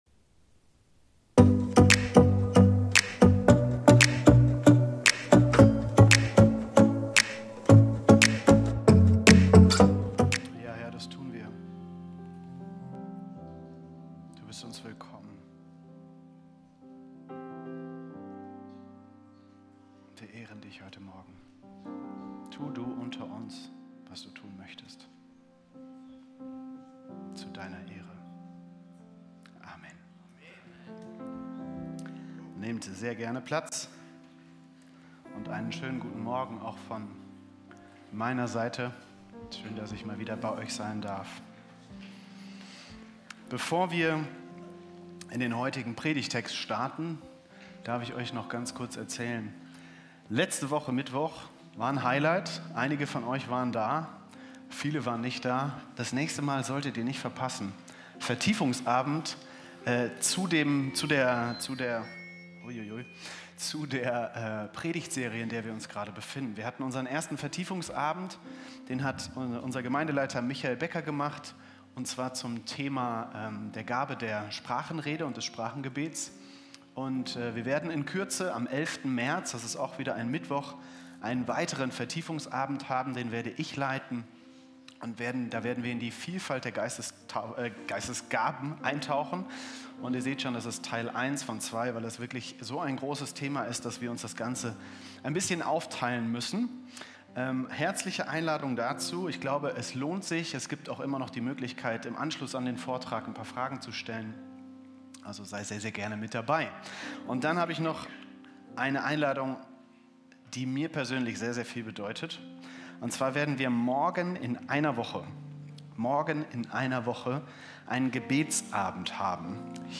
Video und MP3 Predigten
Kategorie: Sonntaggottesdienst Predigtserie: Church on fire